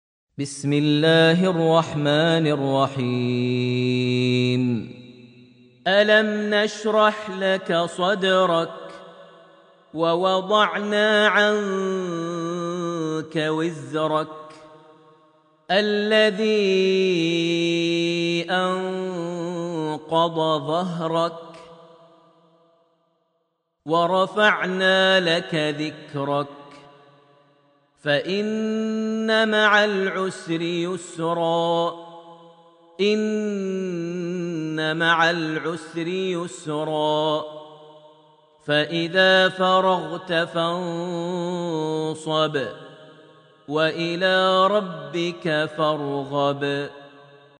Surat Al-Sharh > Almushaf > Mushaf - Maher Almuaiqly Recitations